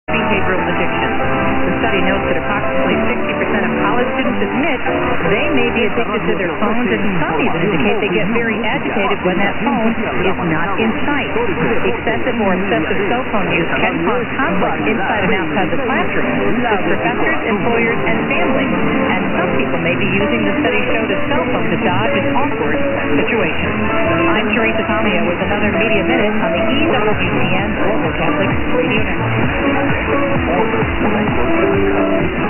1060 | unID | ?, DEC 27 0200 - music, Spanish talk; under WQOM.